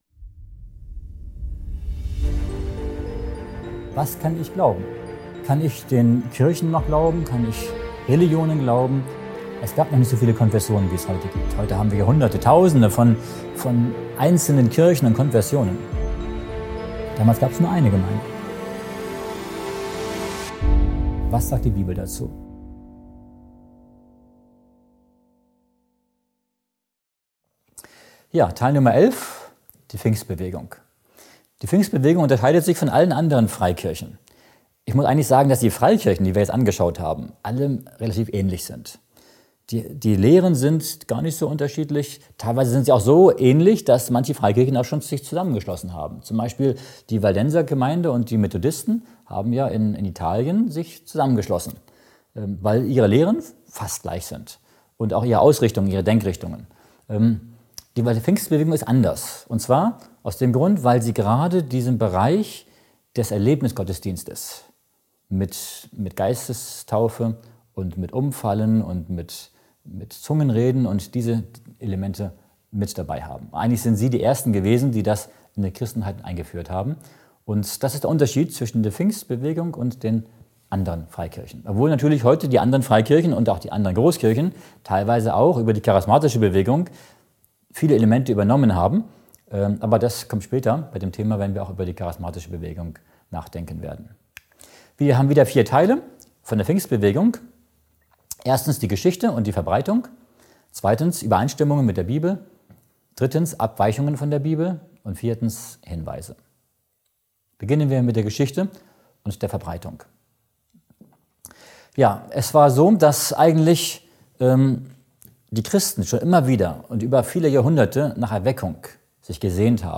In diesem aufschlussreichen Vortrag wird die Pfingstbewegung und deren Unterschiede zu anderen Freikirchen beleuchtet. Der Referent diskutiert die Ursprünge, Entwicklungen sowie die theologischen Unterschiede und Gemeinsamkeiten zur Bibel.